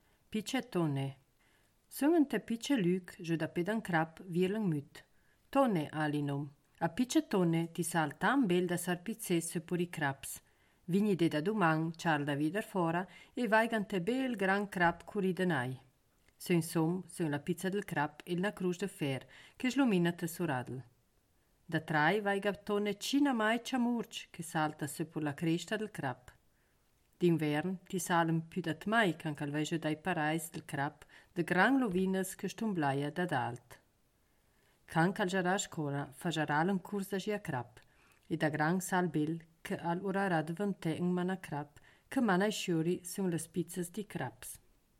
Ladino badiota